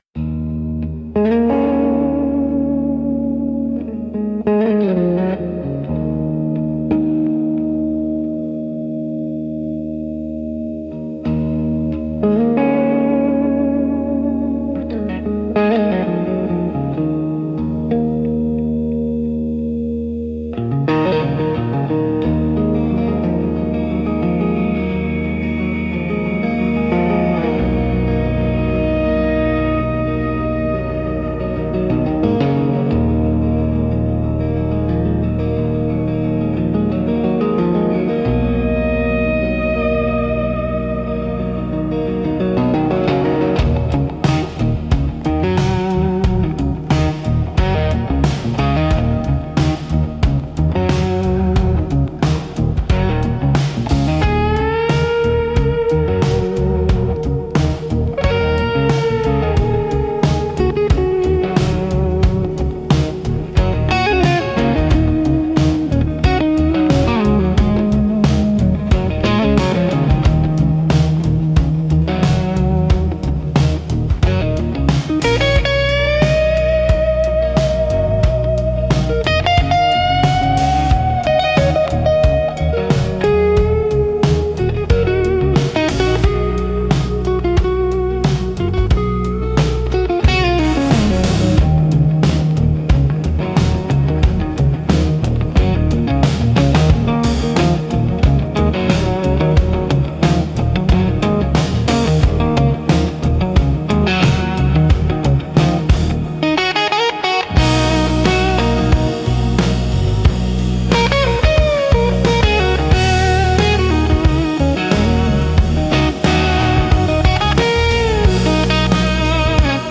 Instrumental - Beneath the Swaying Lines 4.24